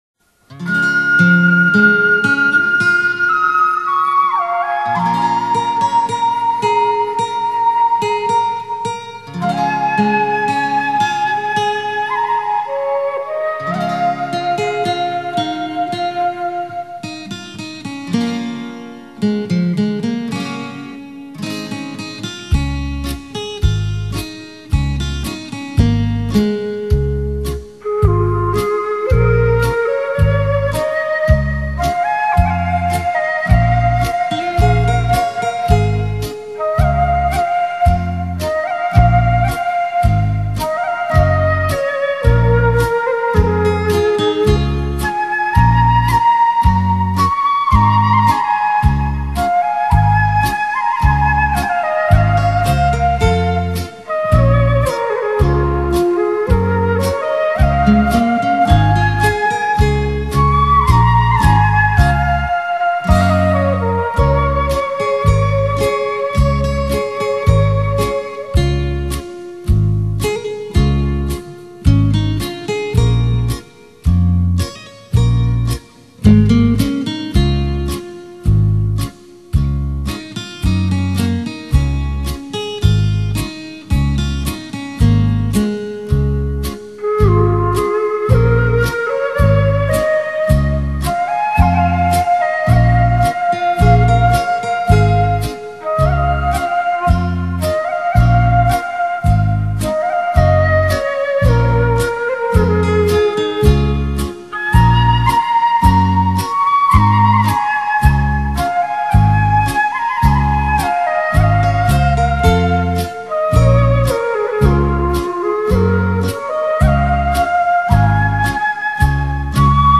有着夜色般的朦胧，听着它---让你进入心境如梦的世界。
像小河流水，它有着清晨露珠般的清澈。